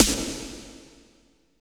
50.04 SNR.wav